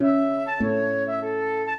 flute-harp
minuet5-12.wav